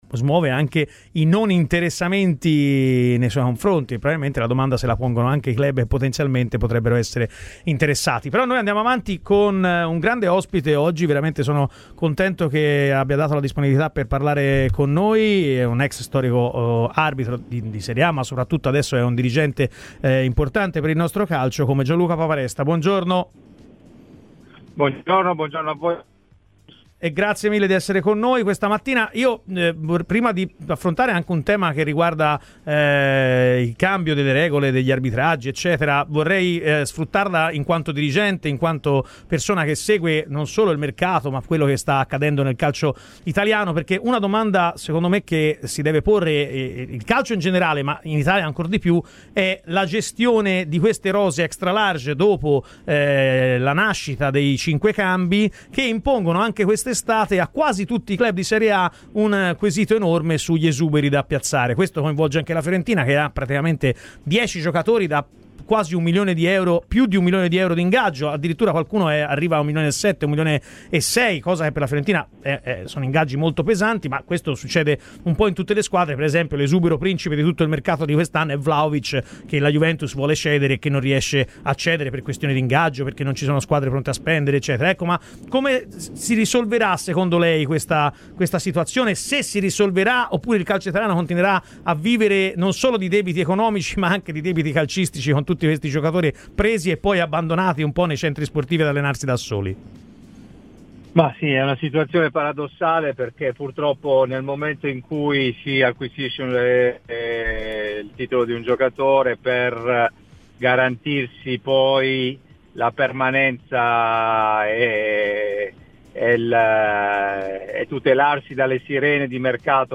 L'ex arbitro, dirigente e presidente Gianluca Paparesta, ha parlato in esclusiva a Radio Firenzeviola durante la trasmissione "Chi si compra?".